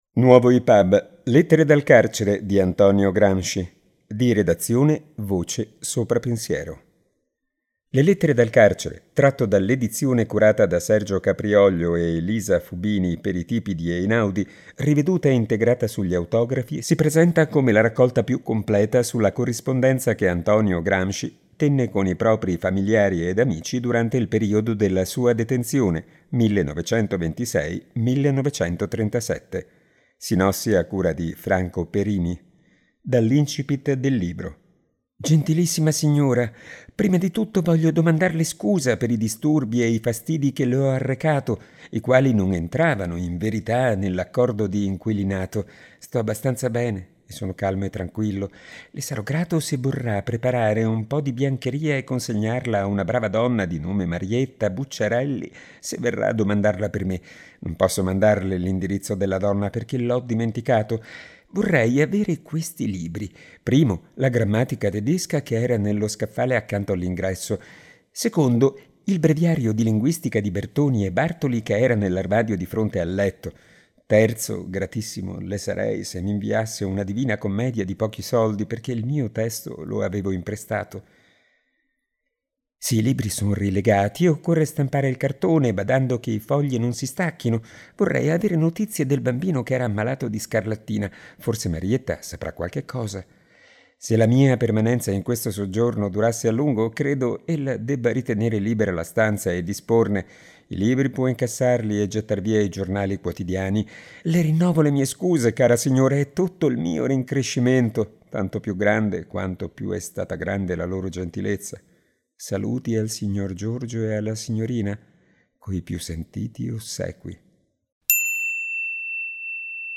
voce